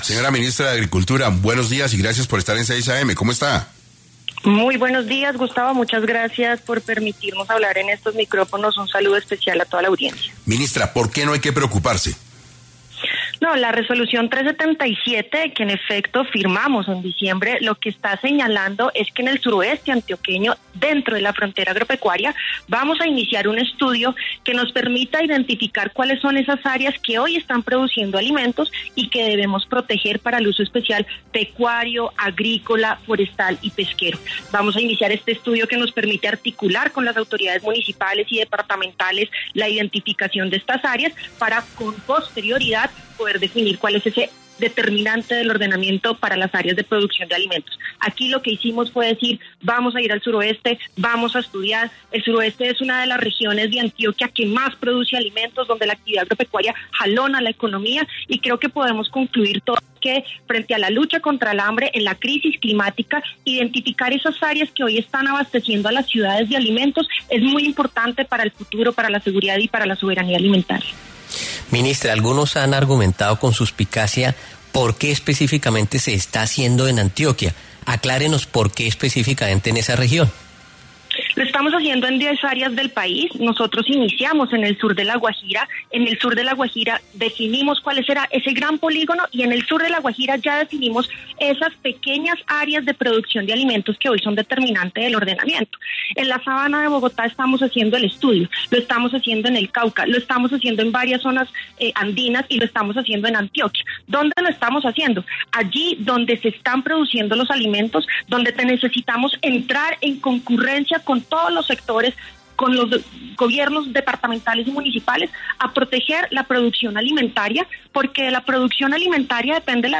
En 6AM de Caracol Radio estuvo Martha Carvajalino, ministra de Agricultura, para hablar sobre una resolución de esta cartera, que según varios congresistas, dejaría en vilo las zonas para fines agrícolas en Antioquia.